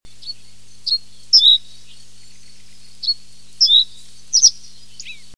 Bruant des roseaux
Emberiza schoeniclus